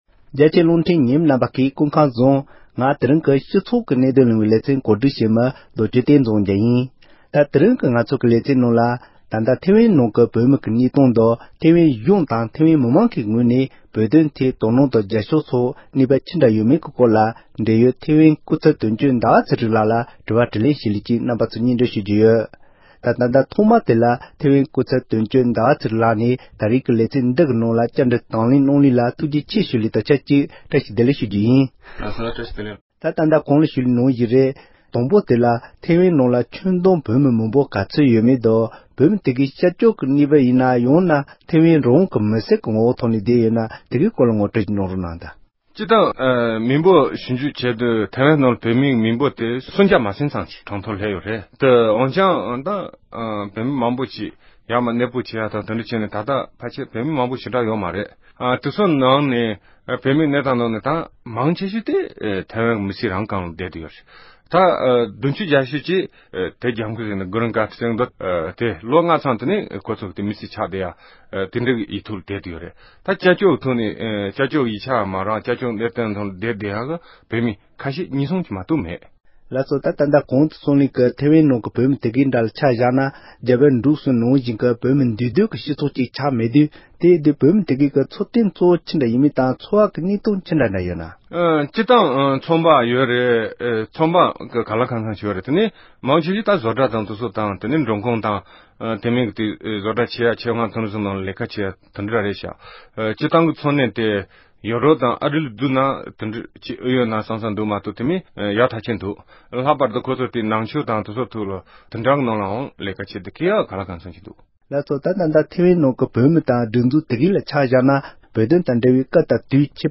བཀའ་འདྲི